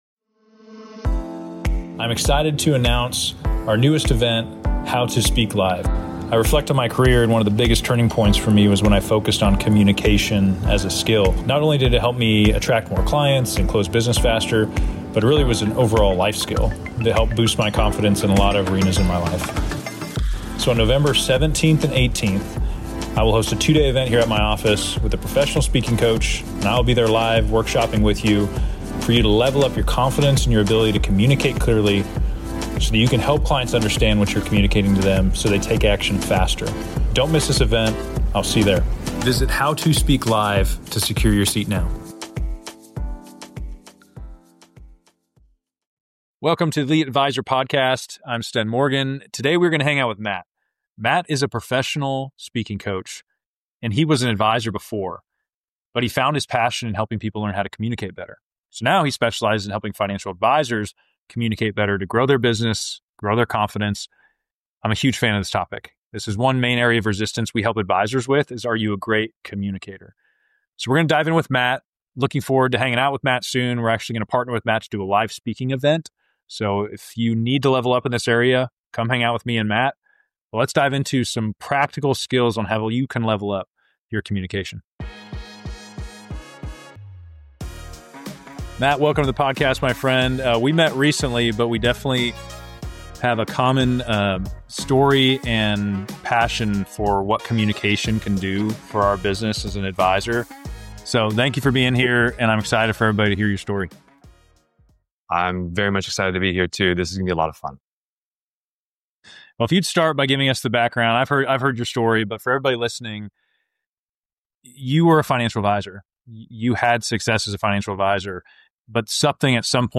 The discussion covers the importance of self-awareness, reflection, and practical strategies for improving communication skills. Both speakers highlight how effective communication can transform client relationships and enhance career growth.